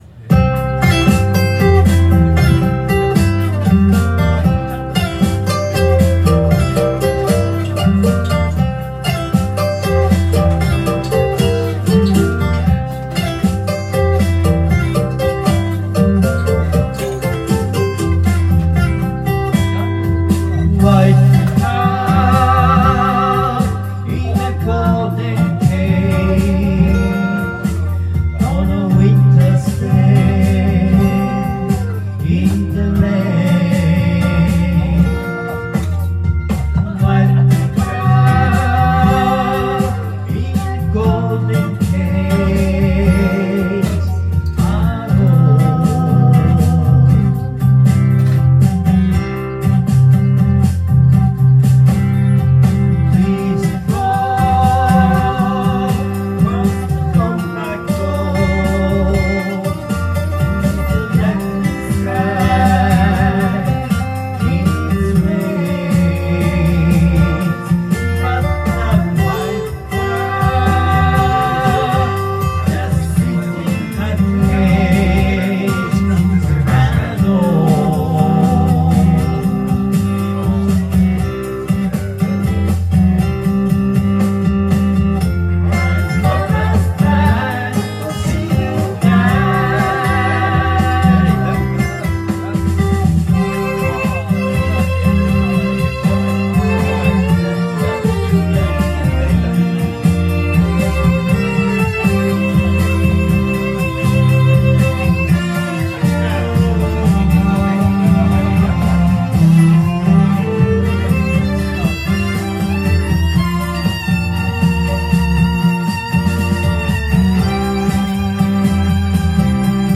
Duet & Chorus Night Vol. 21 TURN TABLE